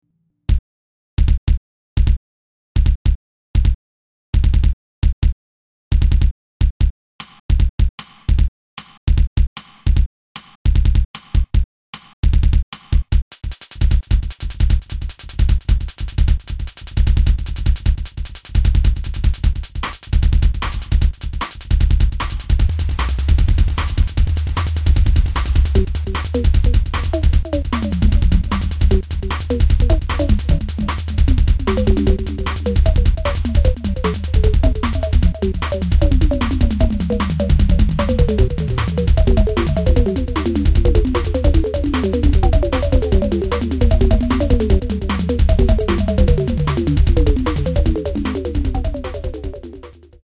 原曲は短調(曲最終のメロがメイン)の曲。